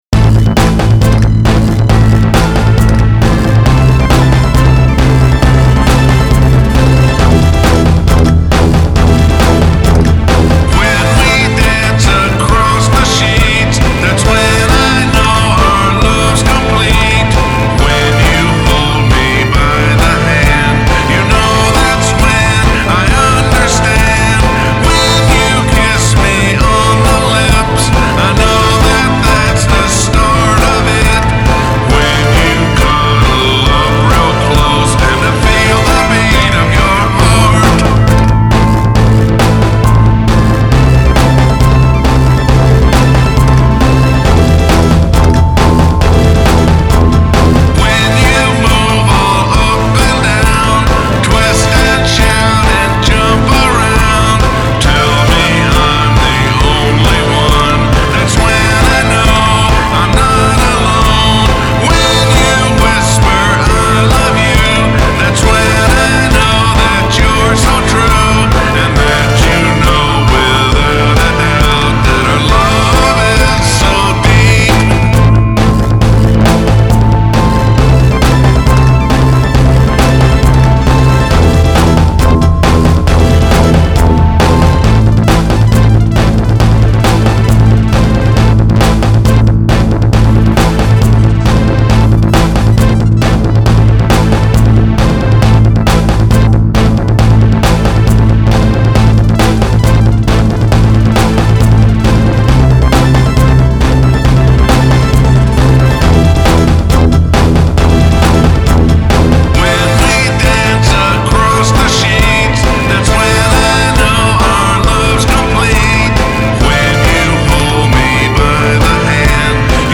which features DISCO and Pop songs .
[NOTE: Everything except the single track lead vocal is done with music notation and IK Multimedia VSTi virtual instruments in NOTION 3, where the NOTION 3 generated audio is recorded via ReWIre (Propellerhead Software) in Digital Performer 7.24 (MOTU) as soundbites, where the various special effects are done in Digital Performer, which is the primary DAW application I use for arranging, producing, mixing, and mastering here in the sound isolation studio . . . ]
-- Basic Rhythm Section and Lead Vocal -- MP3